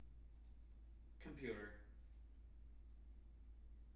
wake-word
tng-computer-395.wav